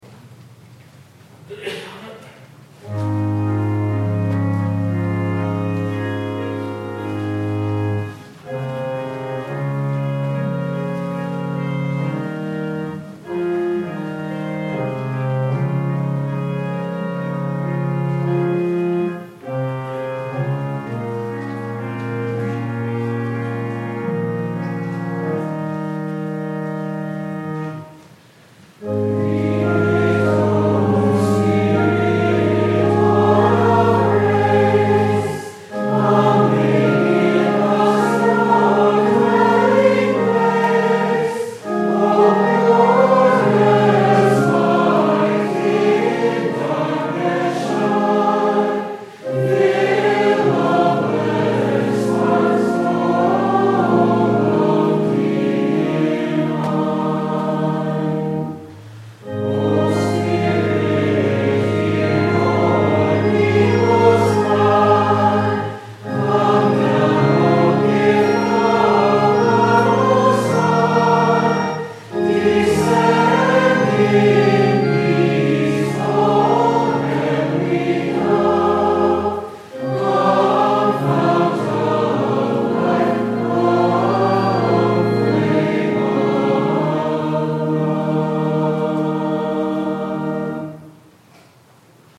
Concert in Celebration of Pentecost
Combined Choirs of the Churches in Rocky Hill